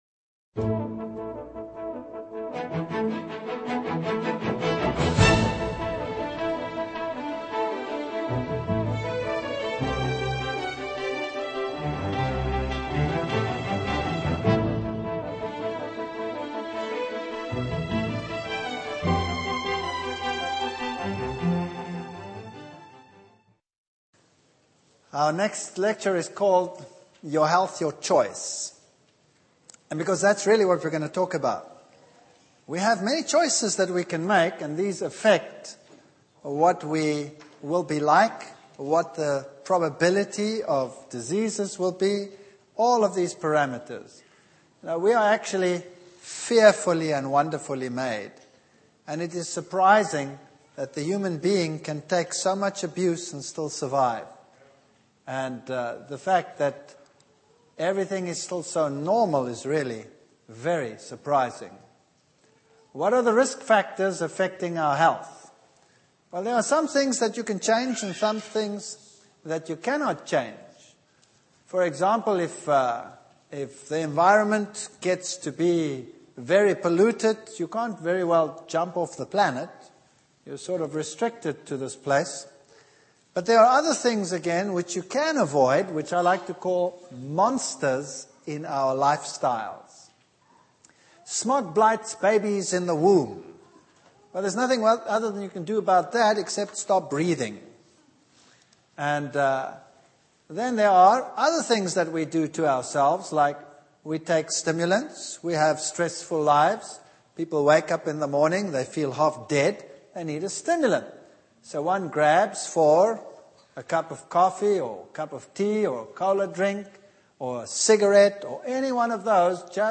Health Seminar